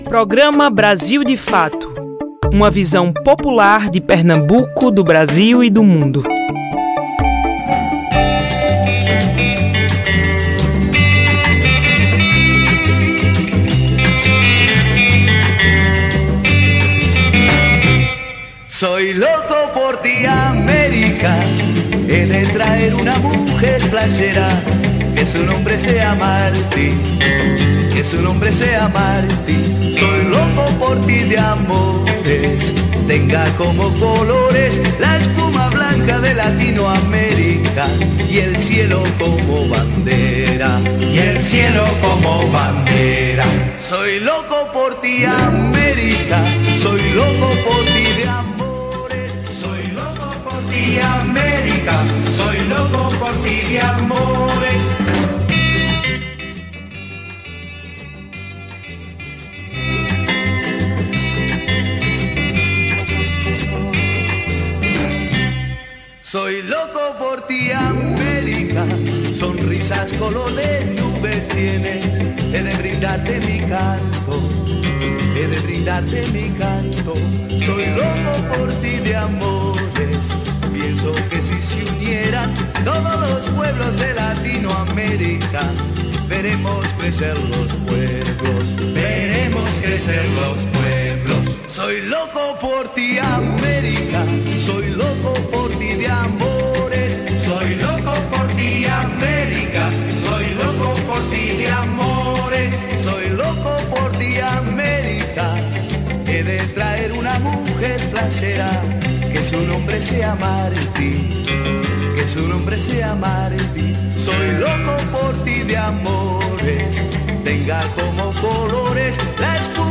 Confira entrevista sobre educação anti-racista no Programa Brasil de Fato PE
Rádio